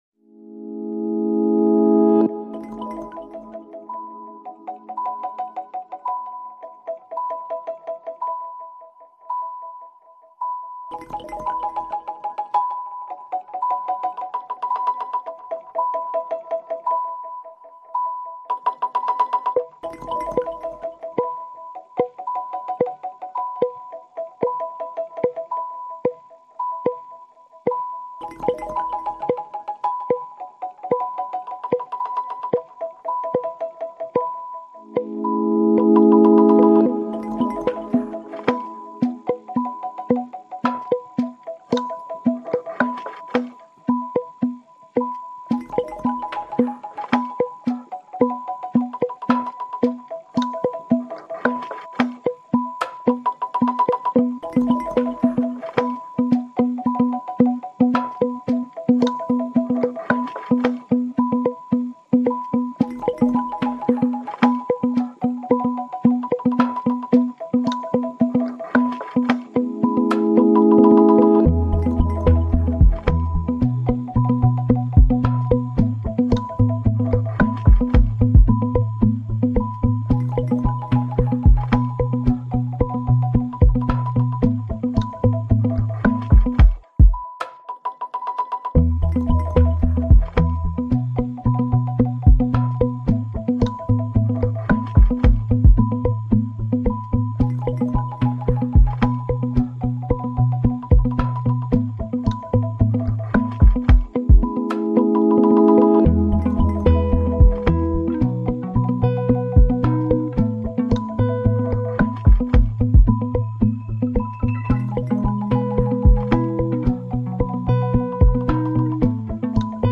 Unser Soundscape